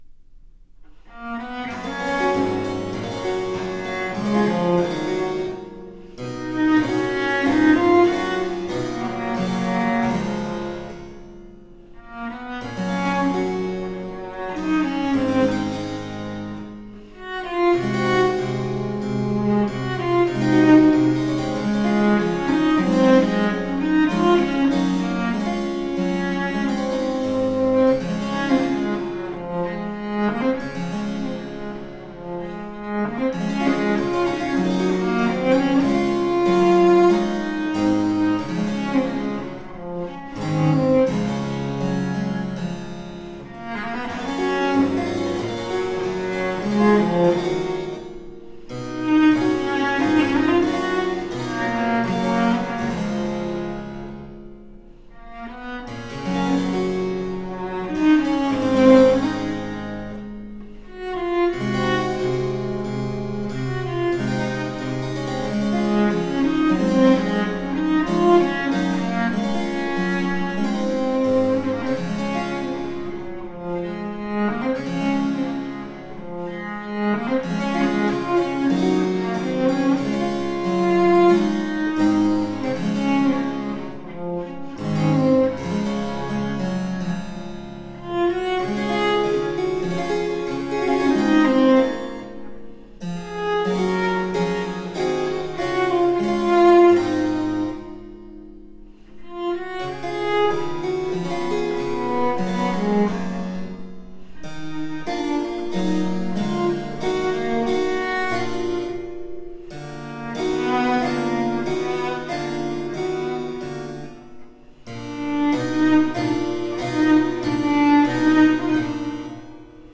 Rare and extraordinary music of the baroque.
Classical, Baroque, Instrumental
Harpsichord